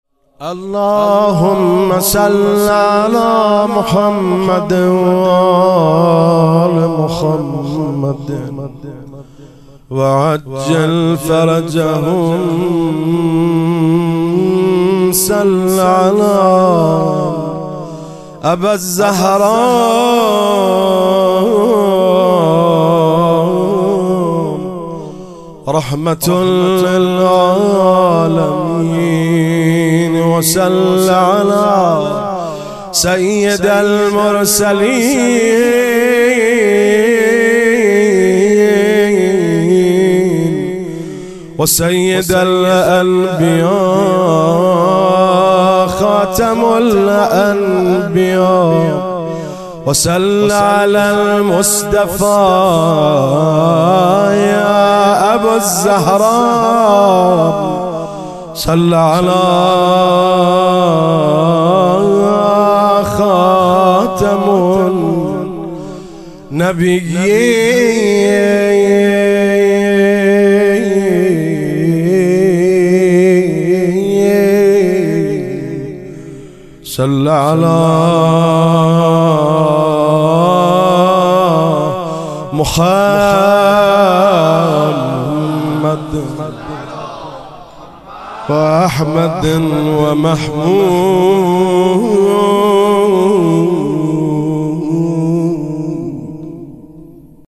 میلاد پیامبر اکرم صلی الله علیه وآله 96 - ابتهال
میلاد حضرت رسول اکرم (صلی الله علیه و آله) و امام صادق (علیه السلام)